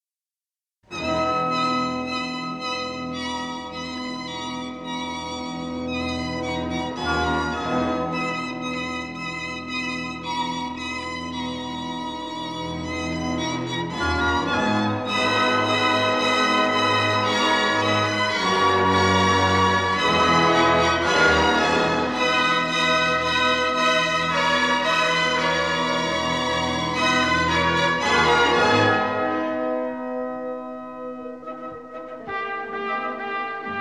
Stereo recording made in July 1959 at the
Walthamstow Assembly Hall, London